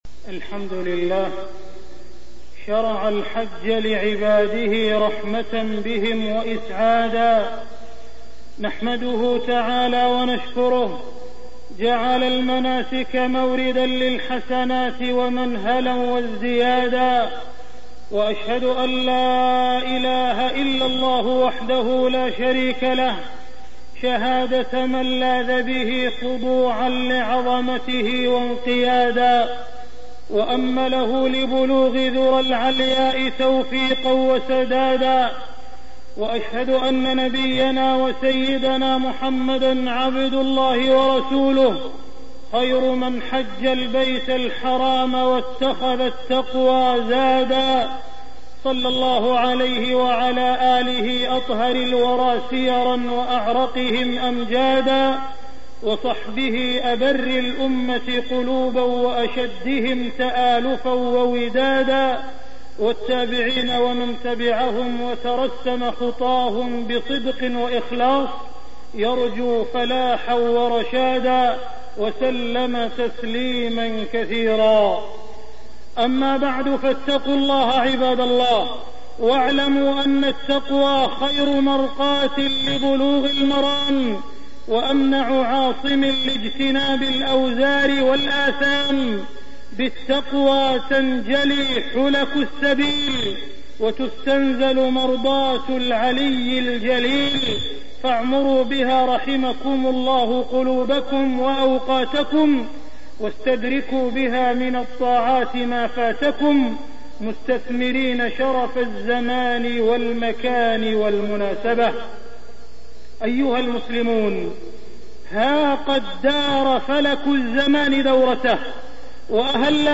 مكة: ماينبغي على الحاج (صوت). التصنيف: خطب الجمعة